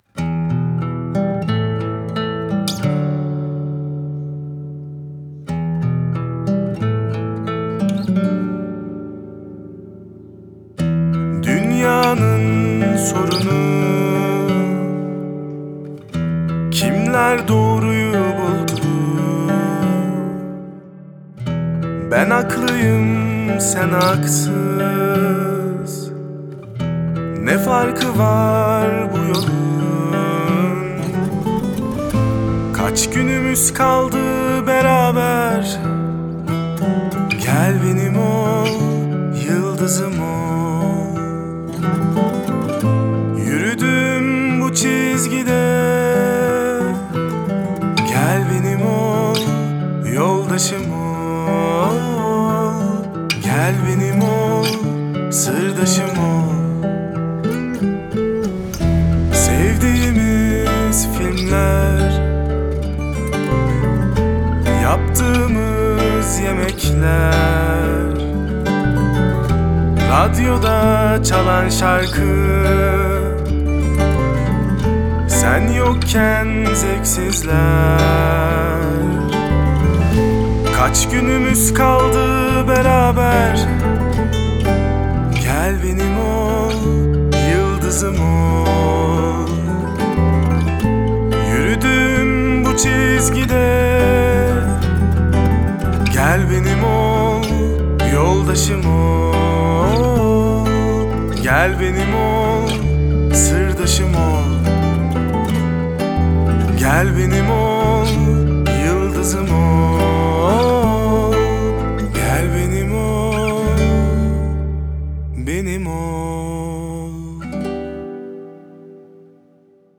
duygusal hüzünlü rahatlatıcı şarkı.